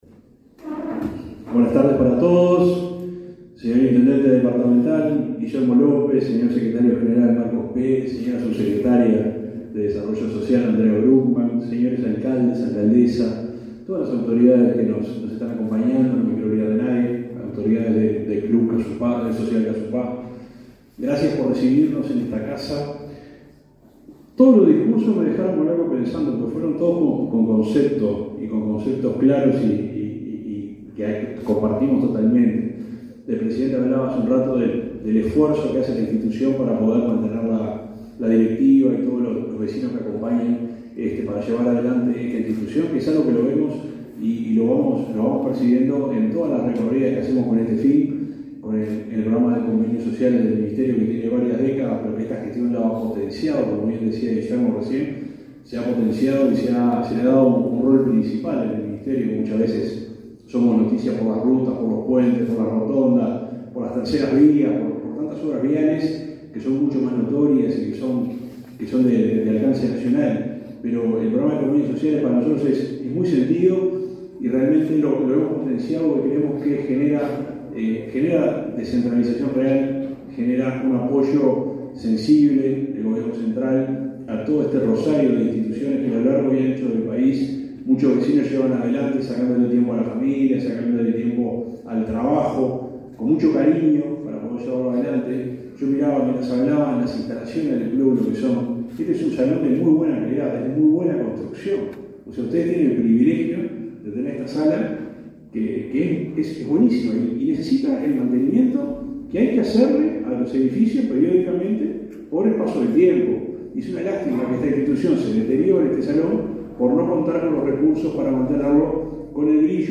Palabras del subsecretario del MTOP, Juan José Olaizola
Palabras del subsecretario del MTOP, Juan José Olaizola 28/09/2023 Compartir Facebook X Copiar enlace WhatsApp LinkedIn El Ministerio de Transporte y Obras Públicas (MTOP) suscribió, este 28 de setiembre, cuatro convenios sociales con instituciones de Florida para apoyar obras y reformas edilicias. En la oportunidad, el subsecretario de la cartera, Juan José Olaizola, realizó declaraciones.